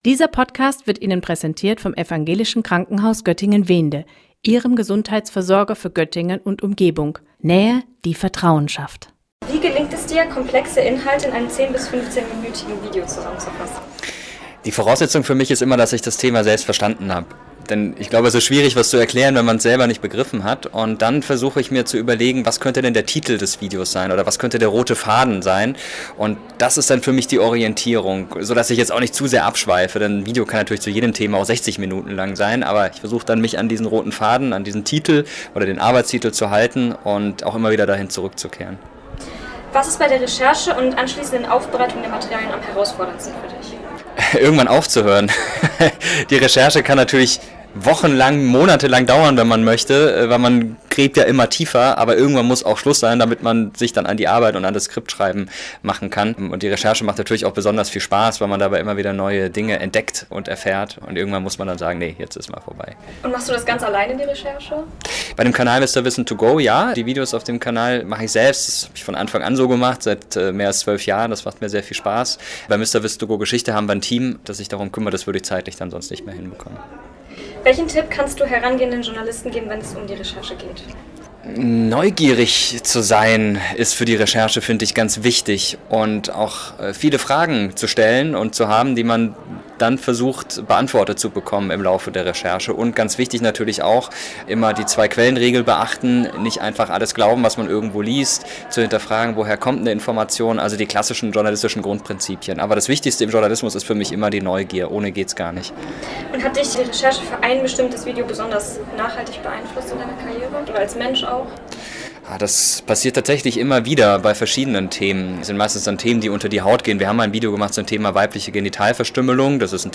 Mirko Drotschmann – YouTuber MrWissen2Go im Gespräch 4:52